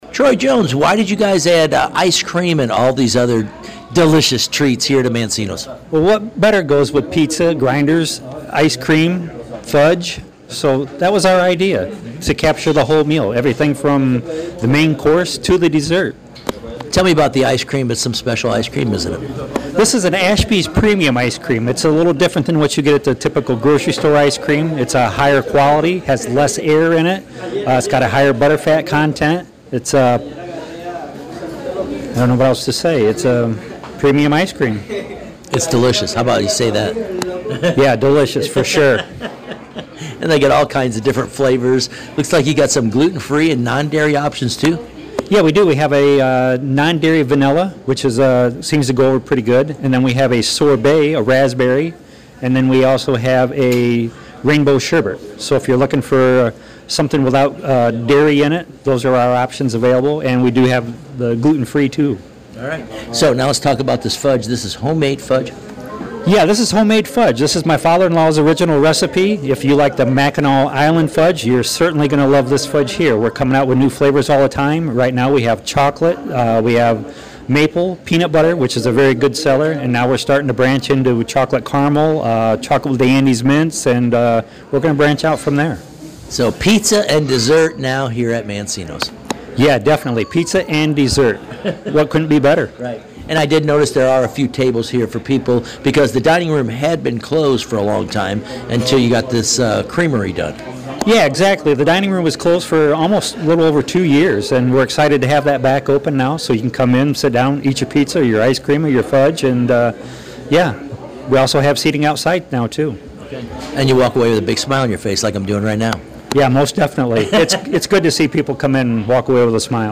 COLDWATER, MI (WTVB) – The Coldwater Area Chamber of Commerce had a ribbon cutting ceremony for the newly opened Mancino’s Creamery on Thursday.